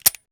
wpn_pistol10mm_firedry.wav